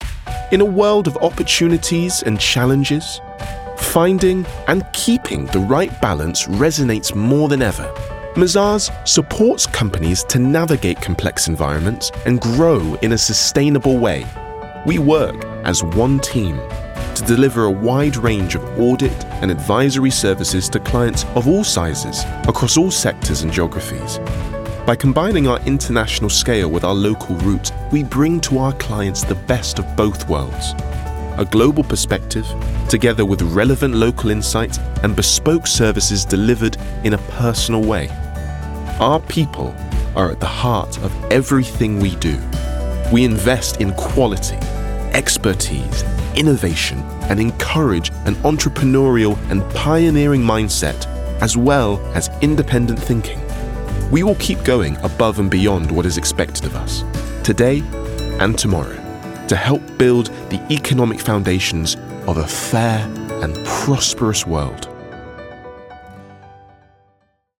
20s-30s. Deep-tone. A collected and silky voice with plenty of humour and warmth. RP.
Corporate